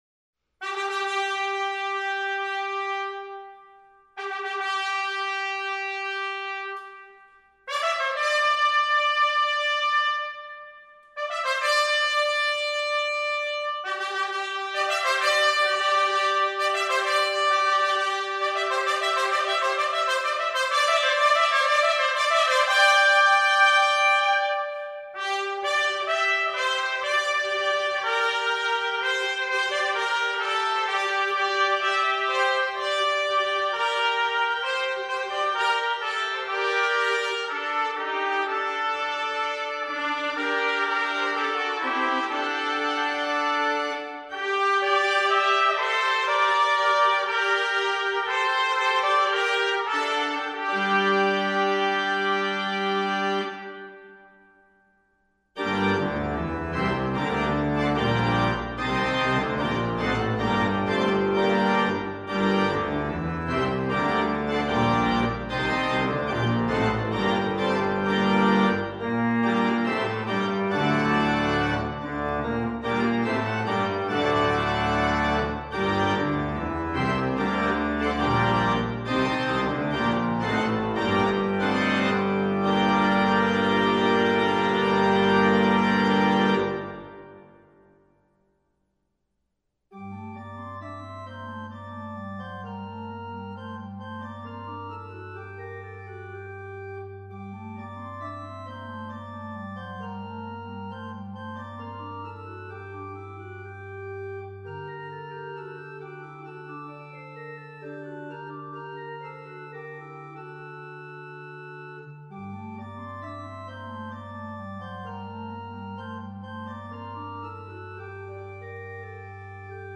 Voicing: Trumpet Duet and Organ